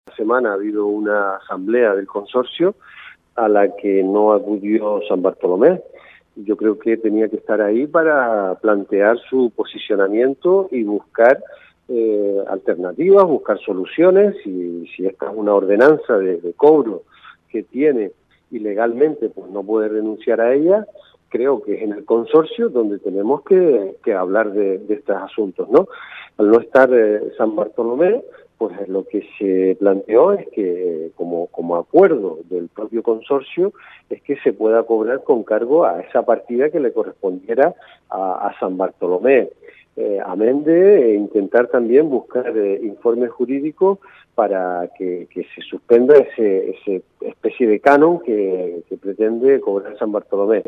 En este sentido, además de Echedey Eugenio, el alcalde de Teguise, Oswaldo Betancort, el de Haría, Marci Acuña, y el de Tinajo, Jesús Machín han expuesto en Crónicas Radio.Cope Lanzarote que consideran que la única opción para que estos proyectos no quiebren sin nacer "es plantear una derrama a los siete ayuntamientos que forman parte del Consorcio, incluido el de San Bartolomé".